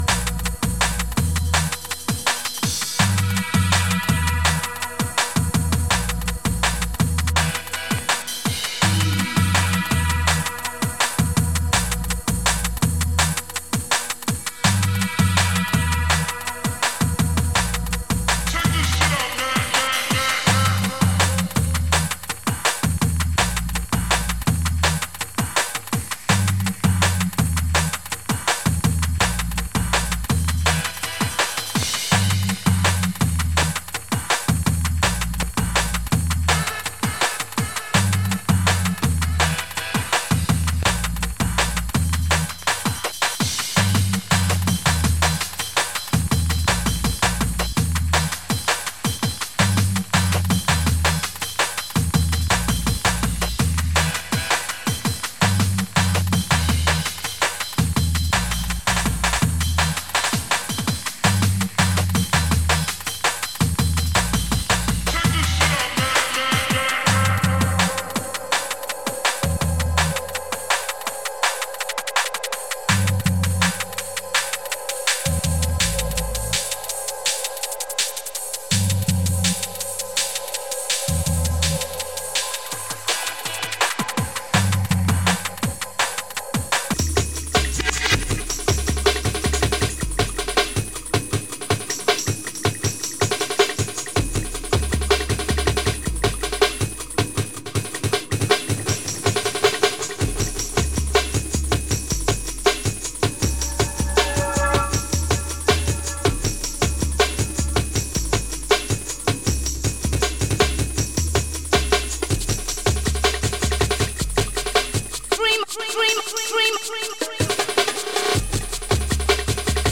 Drum N Bass , Jungle